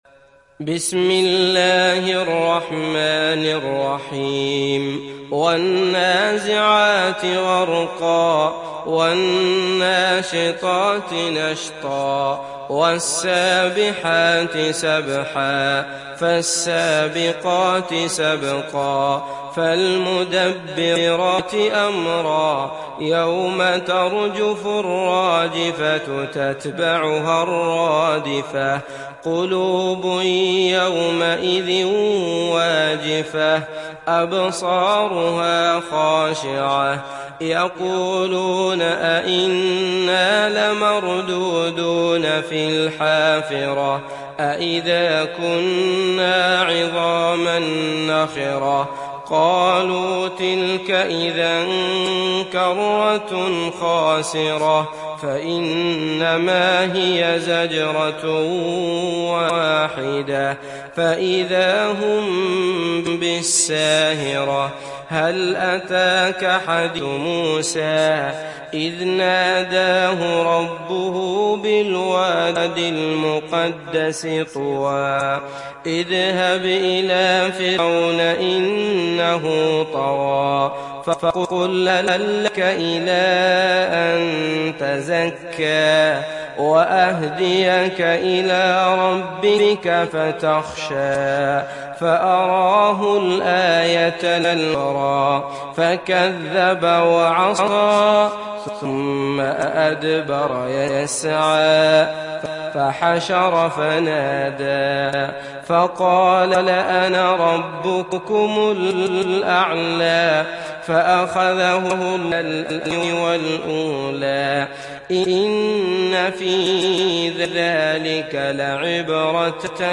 برواية حفص
مرتل